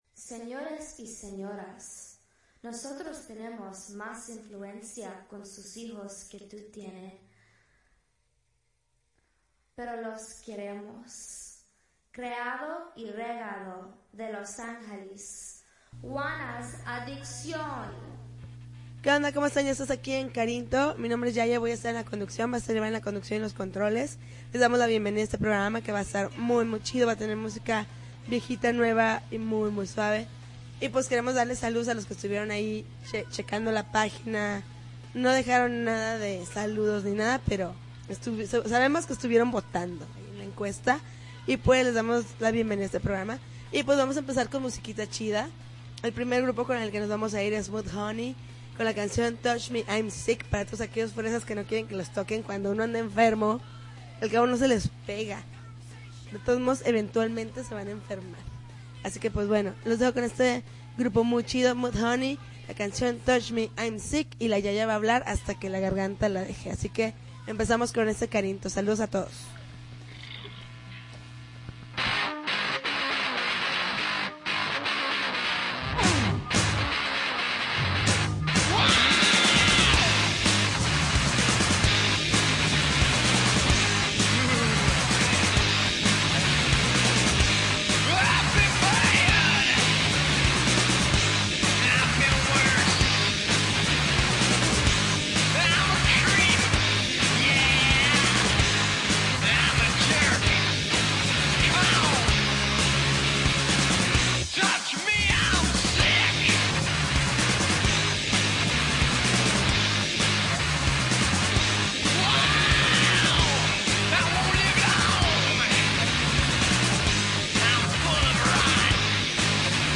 February 28, 2010Podcast, Punk Rock Alternativo